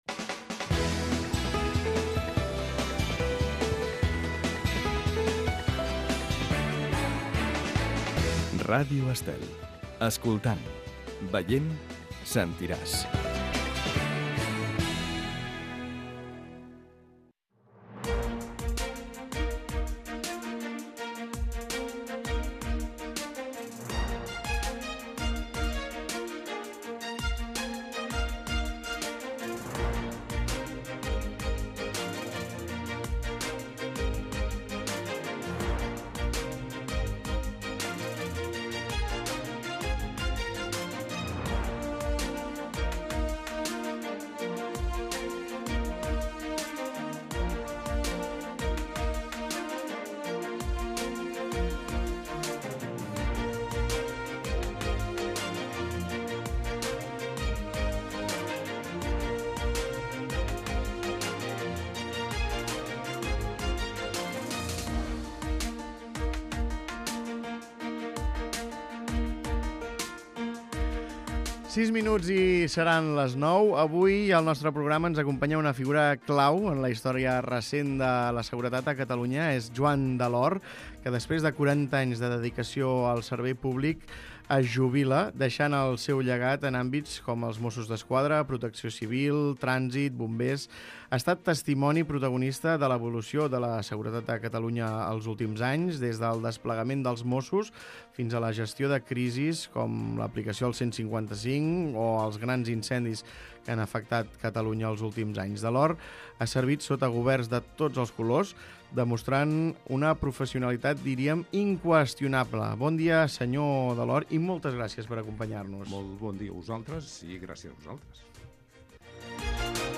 Escolta l'entrevista a Joan Delort